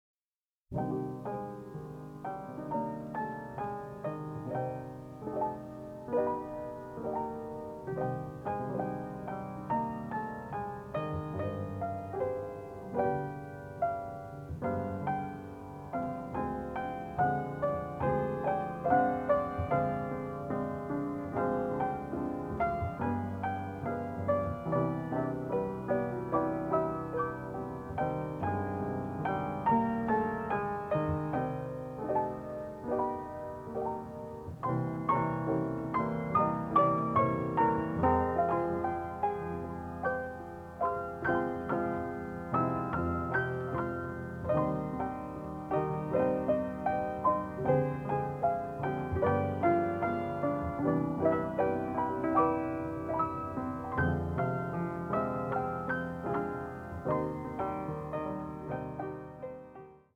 The score also features a bossa, a waltz and a tango.